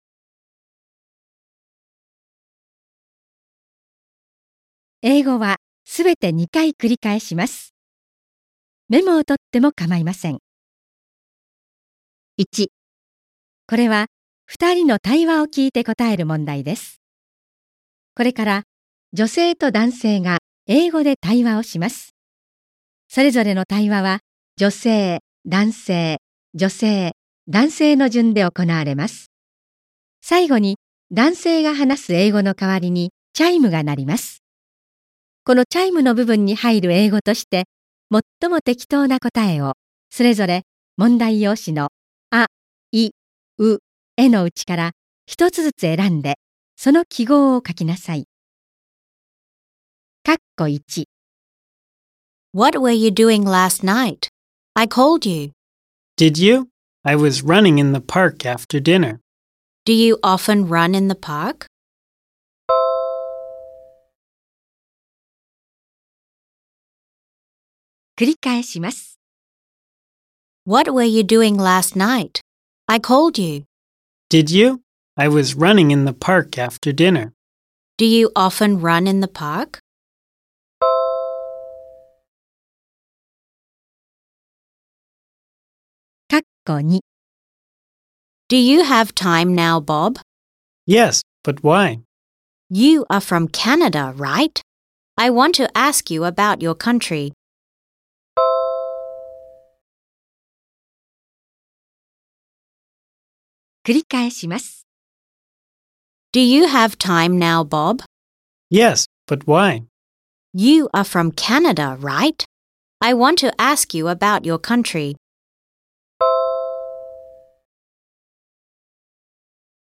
2023年度受験用 岩手県公立高校入試予想問題集 第1回 英語リスニング問題 音声ダウンロード